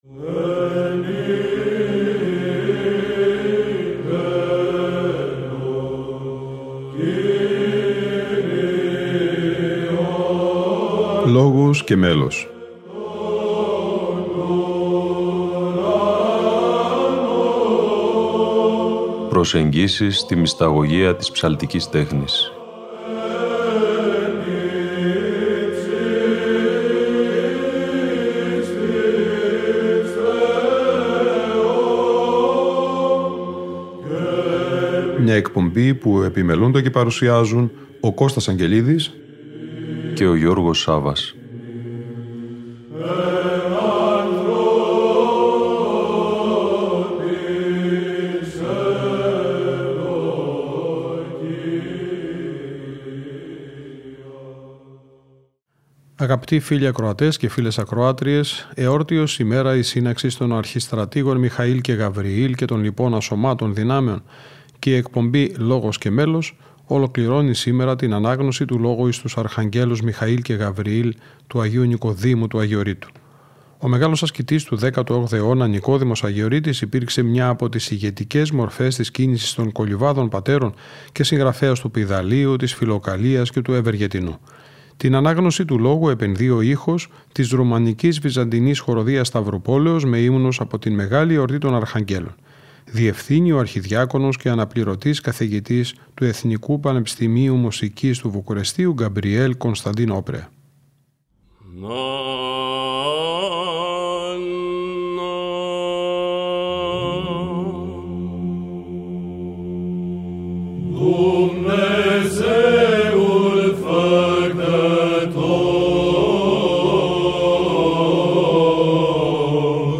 Λόγος εις τους Αρχαγγέλους Νικοδήμου Αγιορείτου - Μέλος Β.Χ. Σταυρουπόλεως (Γ΄)